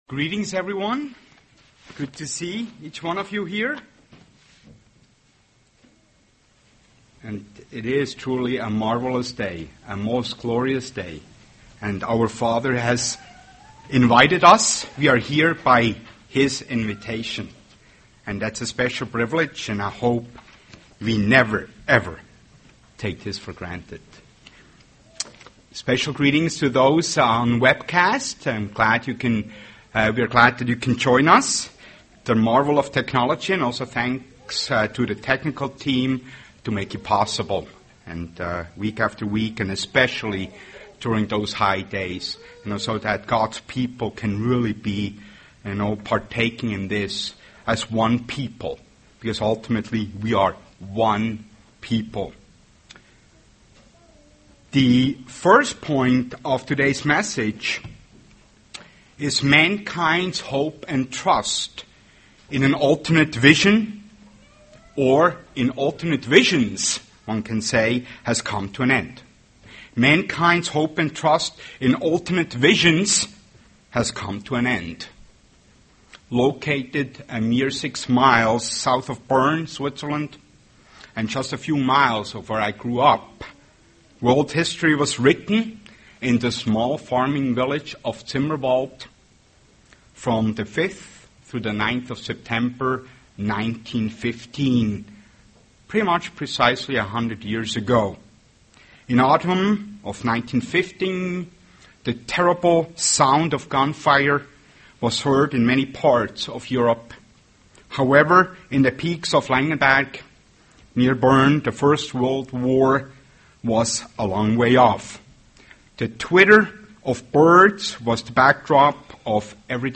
UCG Sermon Studying the bible?
Given in Twin Cities, MN